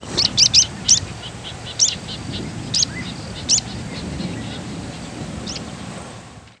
Sprague's Pipit diurnal flight calls
Bird in sustained flight.